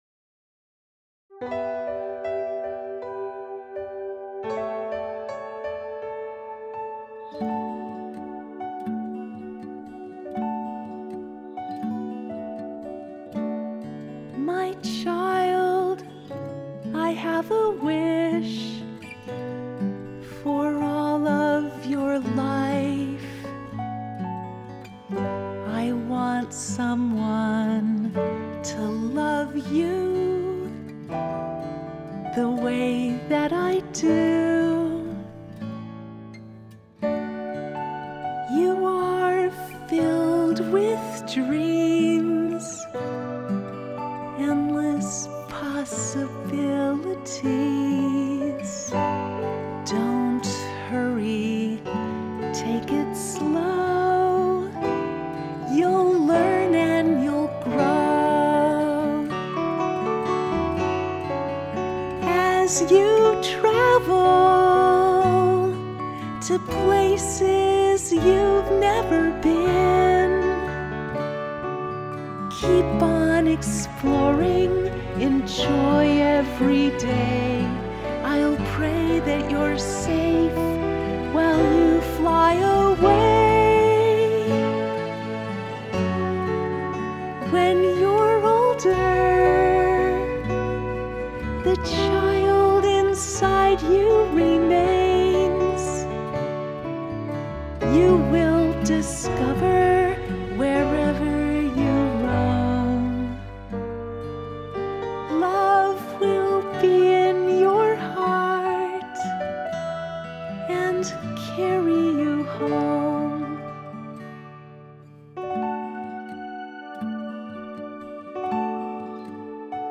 someone-to-love-you-vocal-mix-11-7-17.mp3